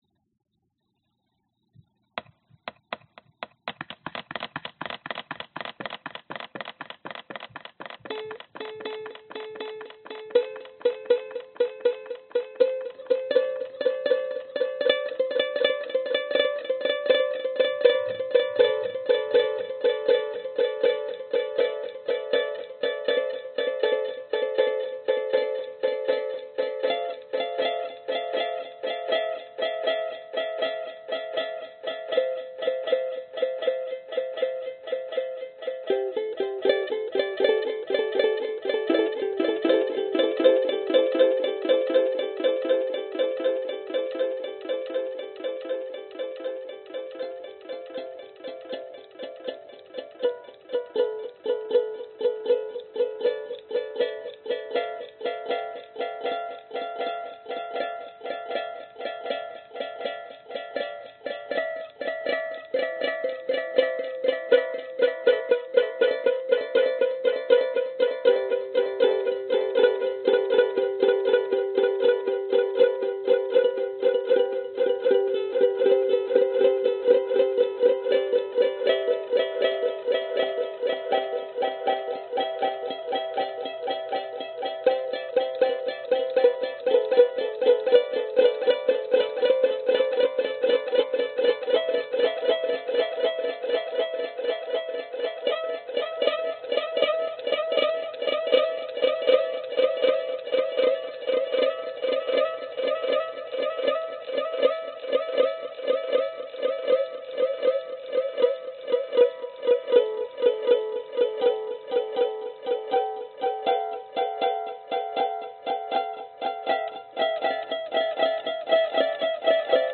banjo1
描述：实验将物体编织到班卓琴的弦上，创造出类似回声的效果。
标签： 班卓琴 回声 试验 细绳 颤音 颤声
声道立体声